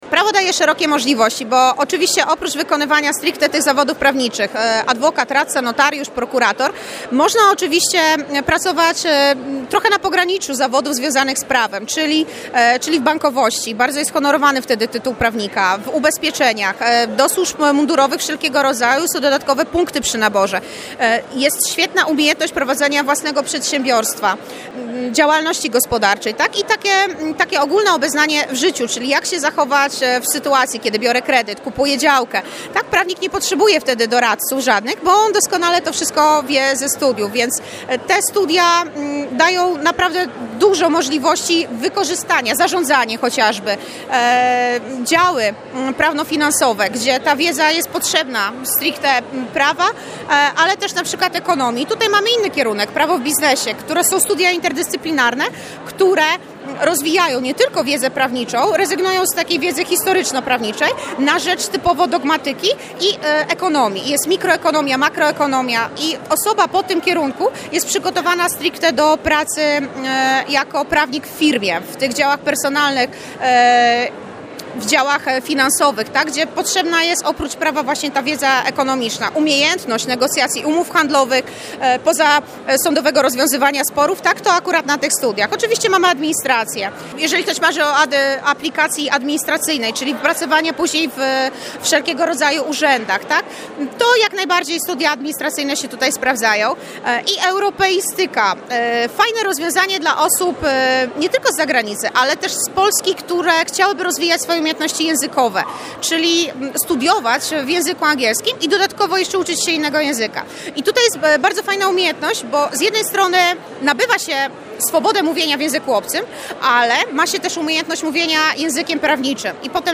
Studia prawnicze i możliwości, jakie dają one w karierze zawodowej, przybliżali gościom XIV Powiatowych Targów Edukacyjnych w Sandomierzu pracownicy i studenci Katolickiego Uniwersytetu Lubelskiego Jana Pawła II.